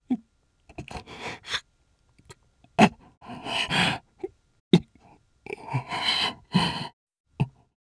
Ezekiel-Vox_Sad_jp.wav